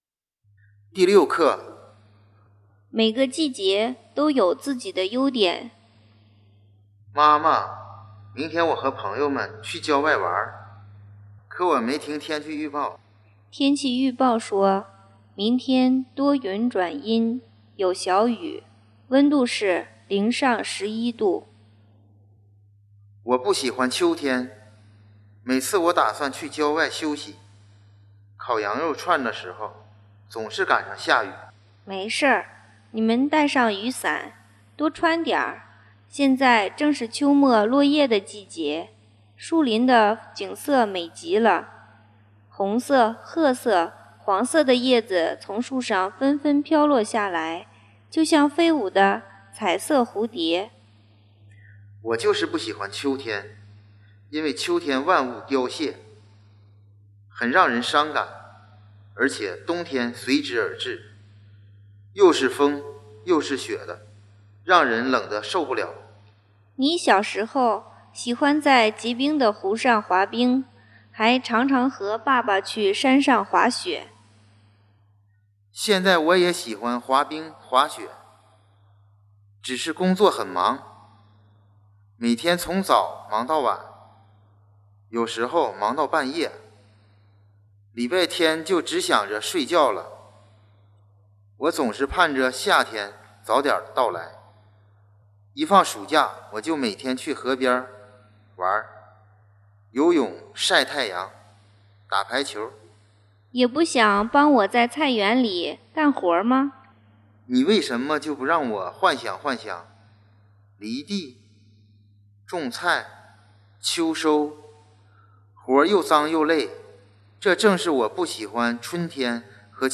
Пособие состоит из 10 уроков, где представлены диалоги, отражающие реальные жизненные ситуации. Издание сопровождается аудиокурсом, содержащим запись нормативного произнесения новых слов и диалогов, а также упражнения на аудирование.